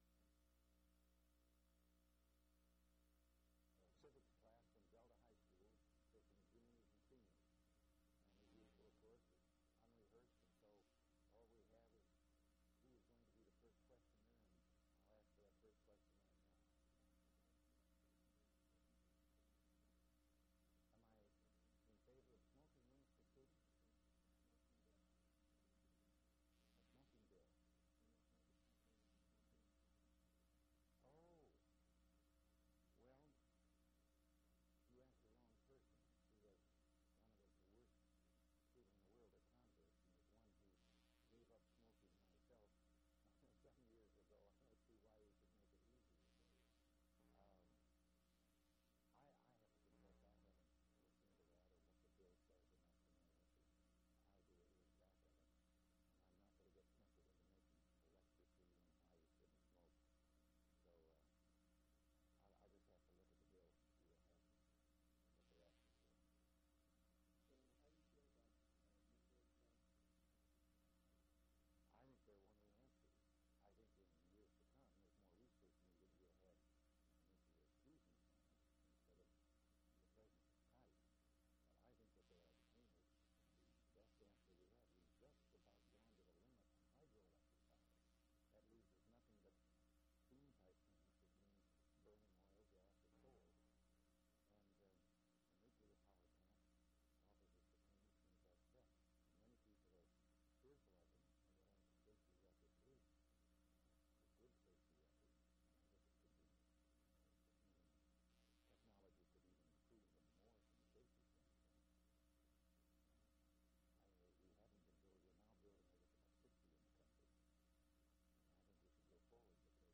Governor Ronald Reagan Question and Answer with students
Audio Cassette Format.